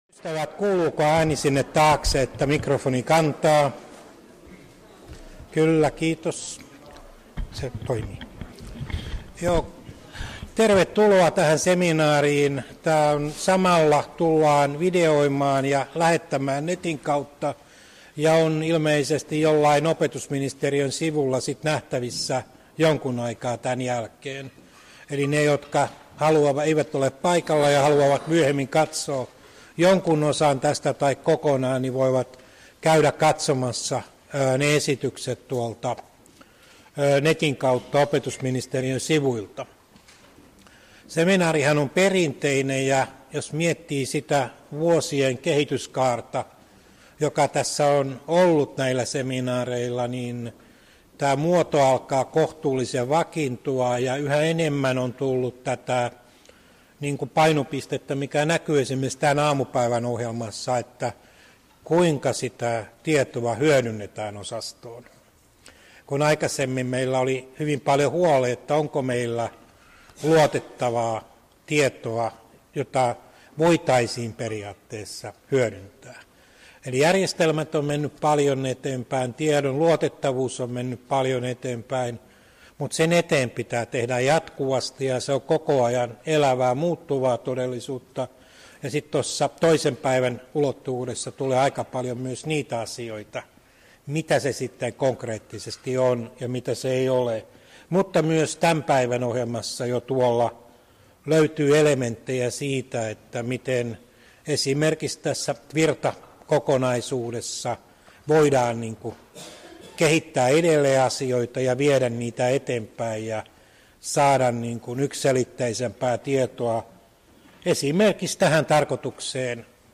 Osa 1: Seminaarin avaus — Moniviestin